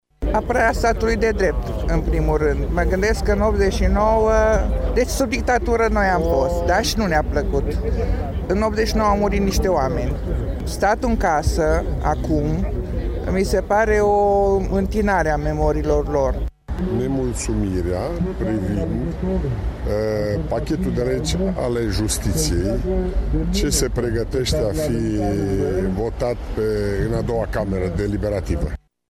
Mitingul a pornit din fața sediului PNL Mureș. Protestatarii au ajuns în fața clădirii Instituției Prefectului județul Mureș.
Oamenii și-au spus nemulțumirile: